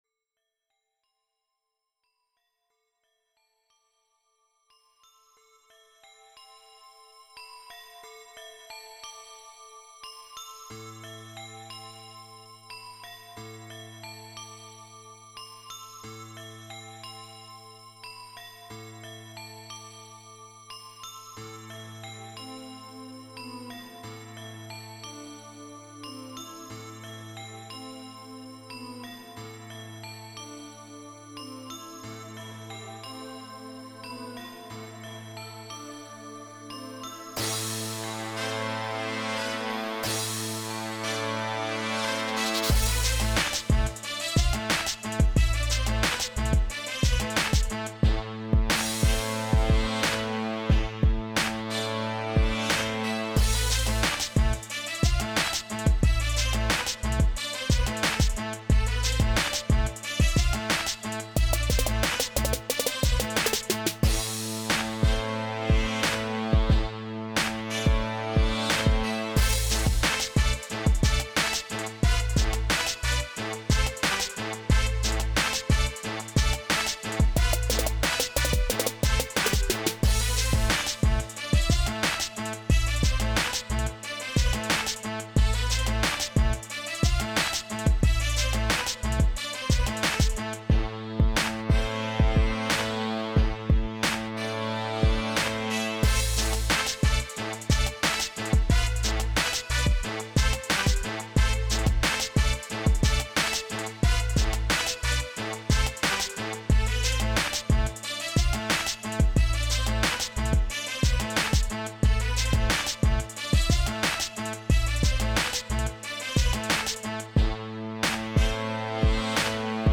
Скачать Минус
Стиль: Hip-Hop